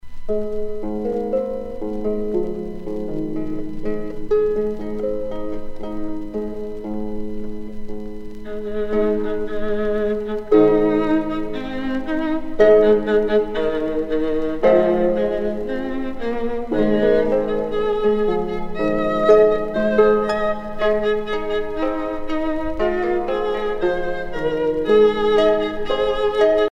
Hébrides (Ecosse)
Pièce musicale éditée